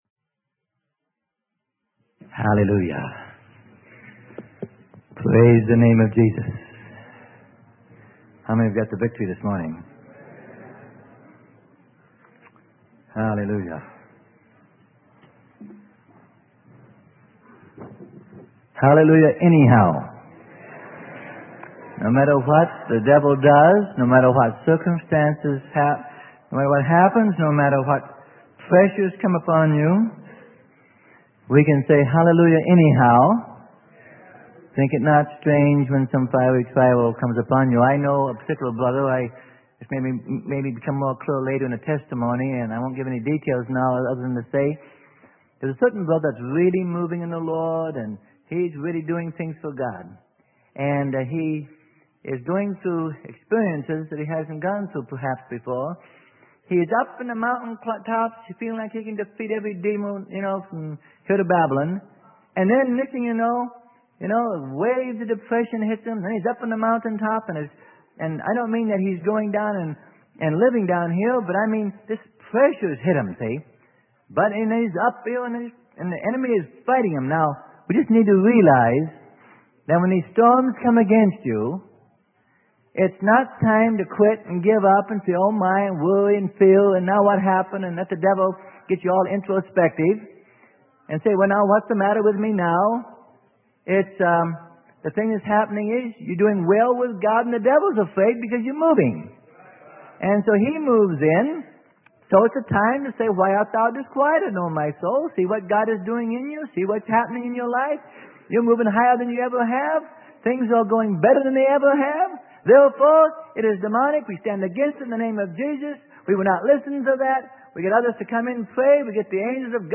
Sermon: Wading Through the Confusion of What is God's Leading - Part 1 - Freely Given Online Library